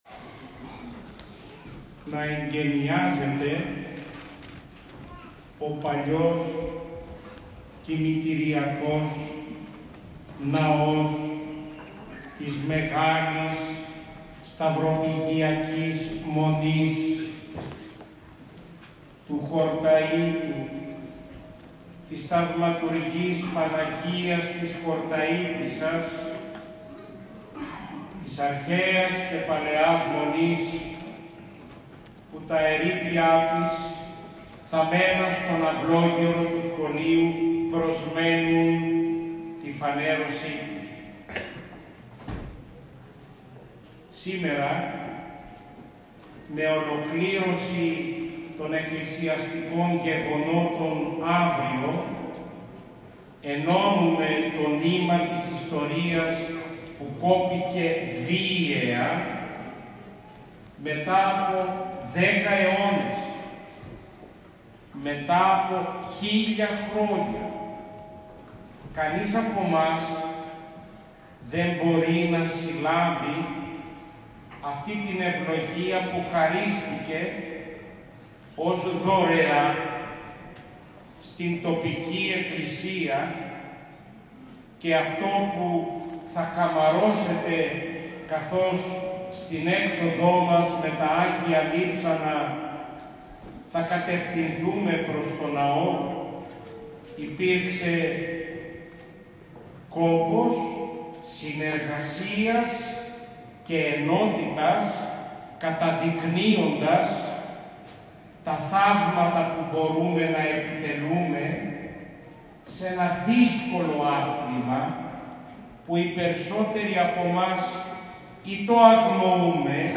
Στον Αρχιερατικό Εσπερινό που τελέστηκε με μεγαλοπρέπεια στον Ιερό Ναό του Αγίου Γεωργίου Χορτιάτη χοροστάτησε την Παρασκευή 5 Μαΐου 2023 ο Σεβασμιώτατος Μητροπολίτης Νεαπόλεως και Σταυρουπόλεως κ. Βαρνάβας.
Ηχητικό αρχείο από το κλείσιμο του Σεβασμιωτάτου